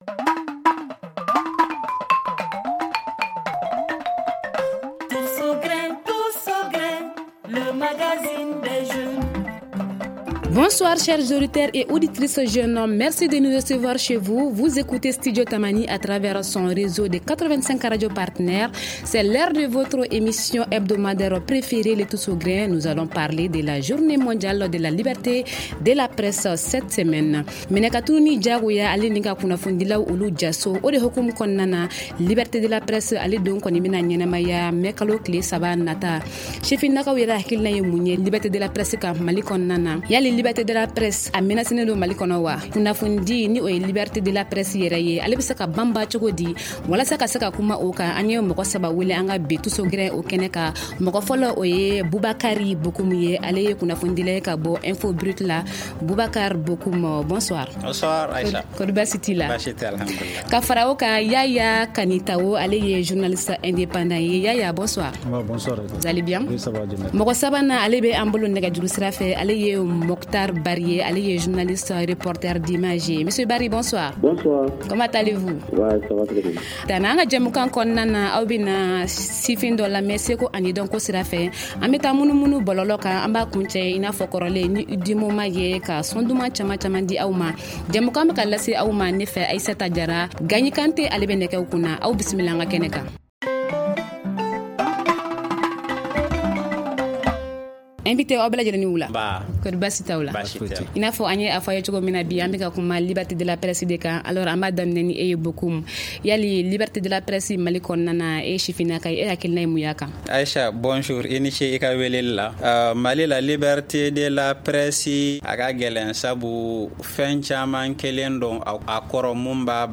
Pour en parler, nos invités sont :